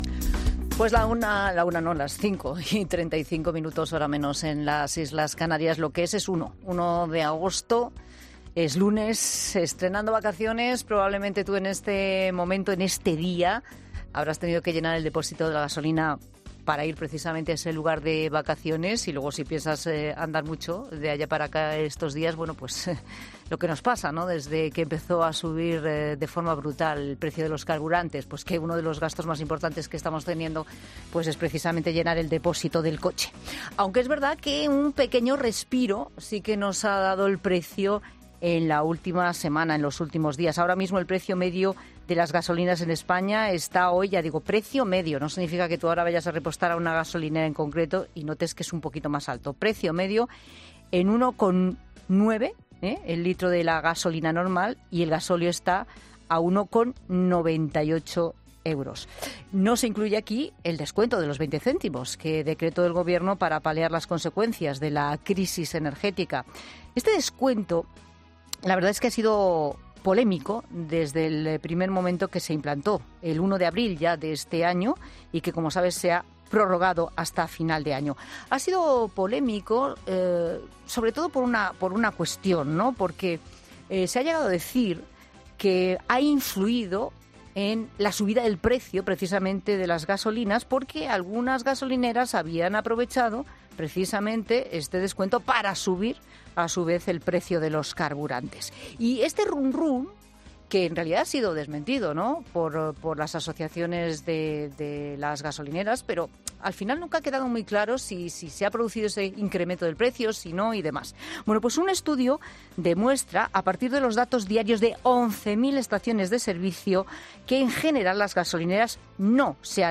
Pincha en el audio para escuchar la entrevista completa en 'La Tarde'.